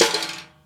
Twisting 2Nite Stix Drop.wav